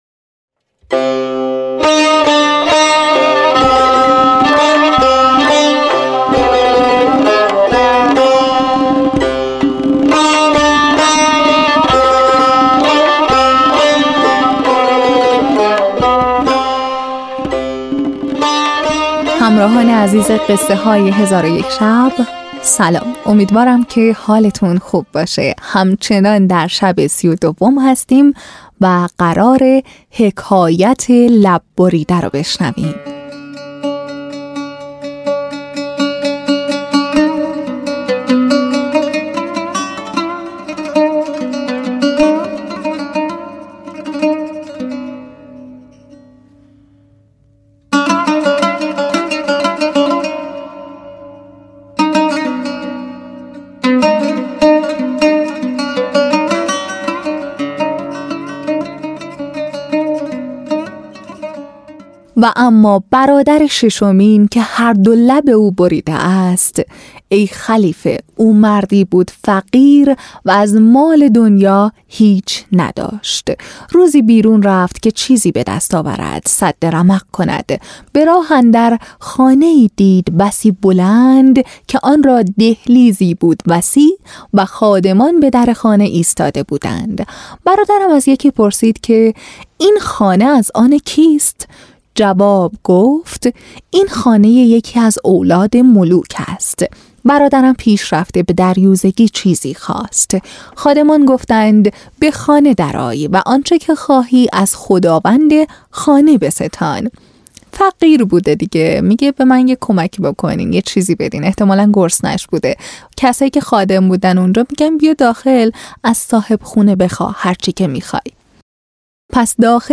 تهیه شده در استودیو نت به نت بر اساس کتاب قصه های هزار و یک شب